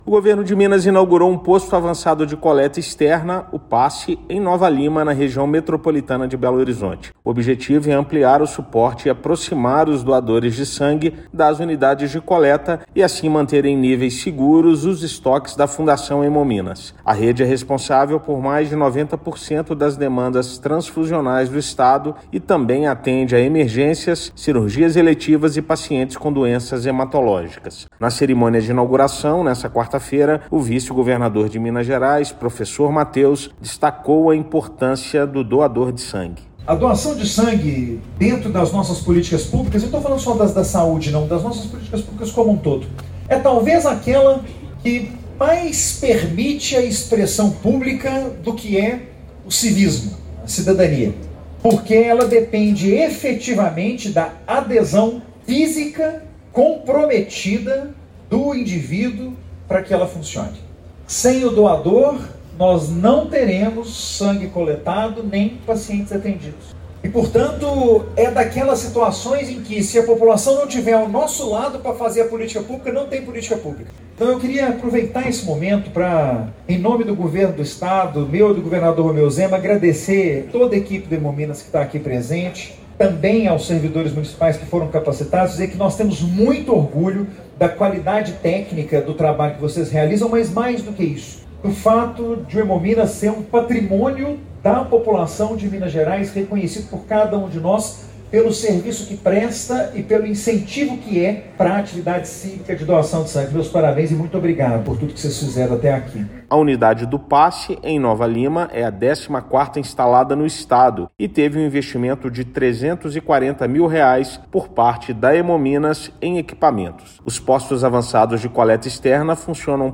Unidade amplia serviço de coleta de sangue na Região Metropolitana de Belo Horizonte (RMBH); estado já conta com 14 postos para doação. Ouça a matéria de rádio: